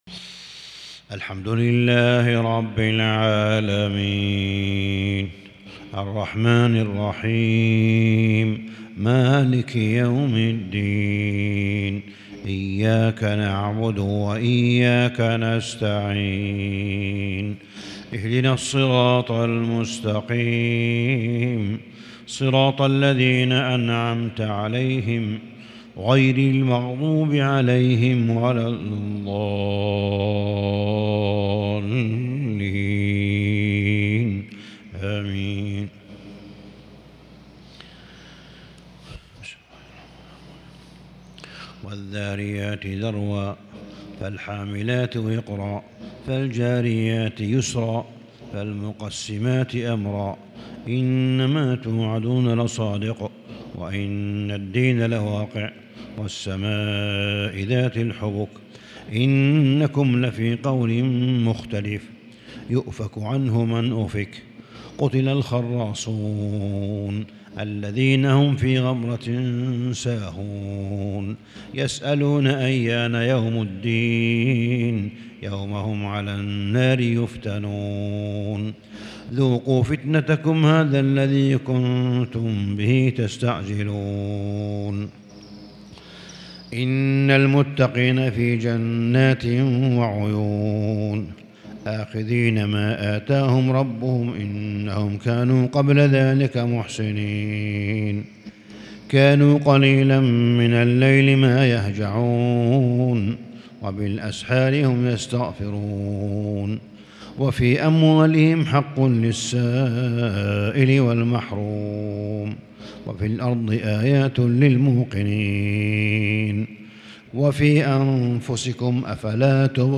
صلاة الفجر من سورة الذاريات 20 رمضان 1442هـ |2-5-2021 Fajr prayer from Surat Al-Thariyat > 1442 🕋 > الفروض - تلاوات الحرمين